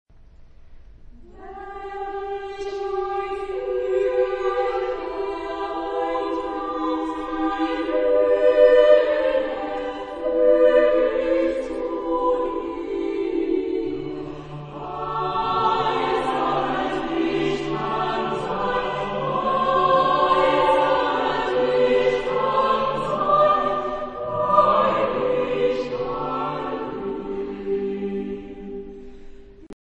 Genre-Style-Form: Secular ; Popular ; Folk music
Type of Choir: SAATB (div)  (5 mixed voices )
Tonality: G major
Discographic ref. : 7. Deutscher Chorwettbewerb 2006 Kiel